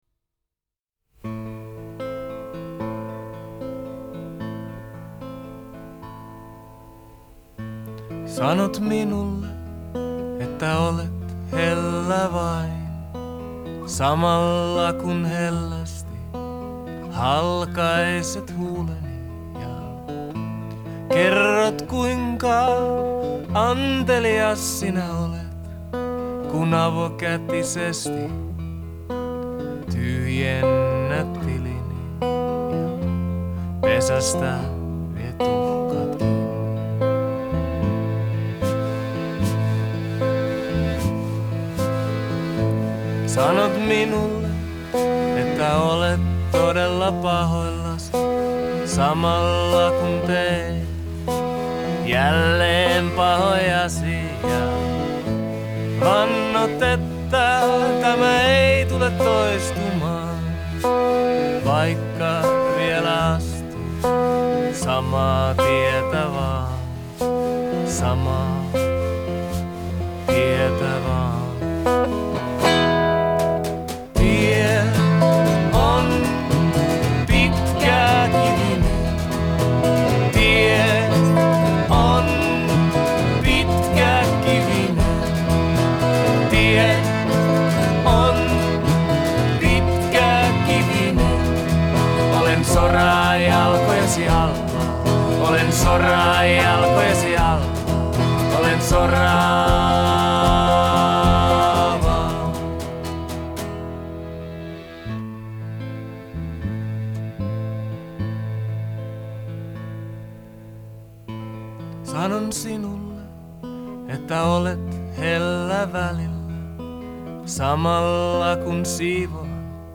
вокал и гитара
аккордеон и вокал
- барабаны
Genre: Folk, World